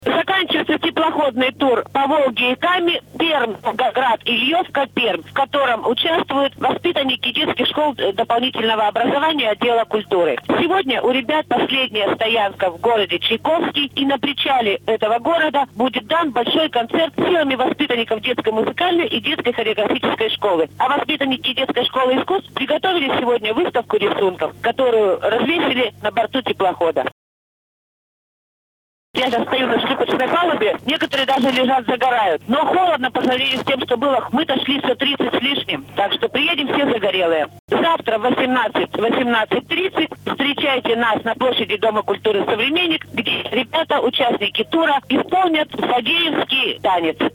«запись с телефона»).